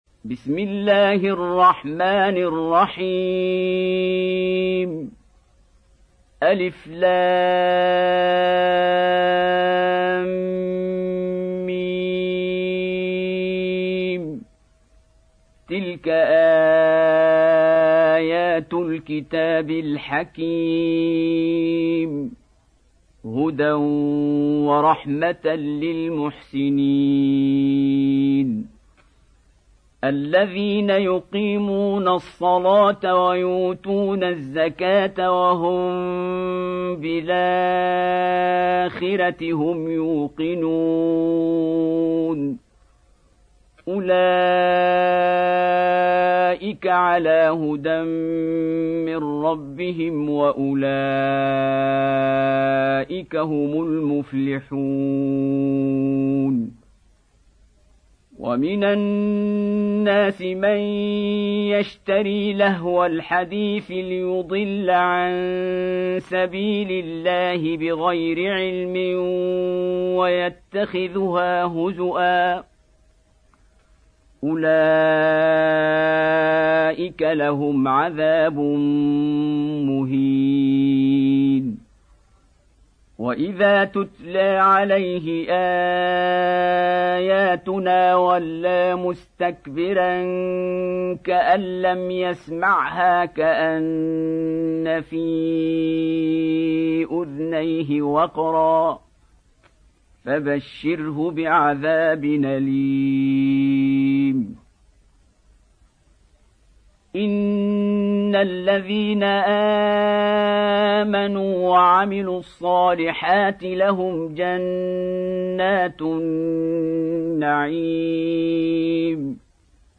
Surah Luqman Beautiful Recitation MP3 Download By Qari Abdul Basit in best audio quality.